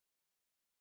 ຟັງການສຳພາດ ລາຍງານດ້ານສິດທິມະນຸດ ຂອງ ຄະນະ ພັນທະມິດ ເພື່ອປະຊາທິປະໄຕໃນລາວ